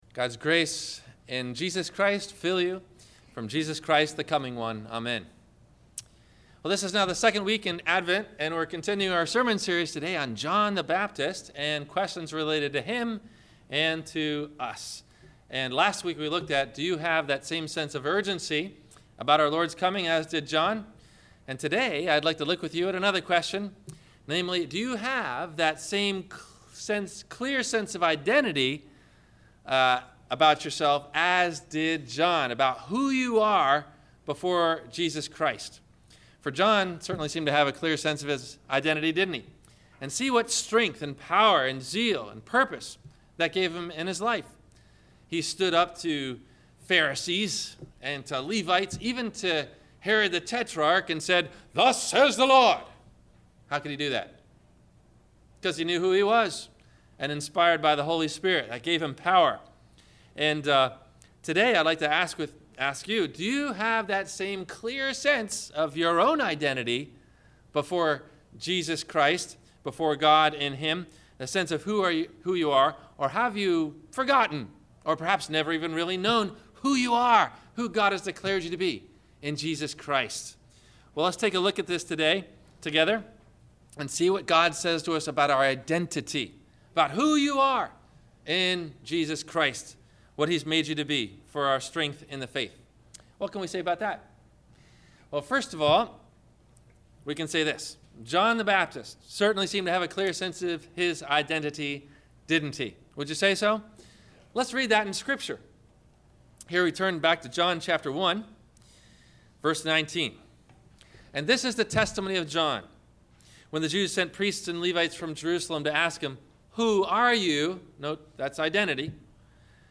Do You Know Who You Are in Christ? – Sermon – December 07 2014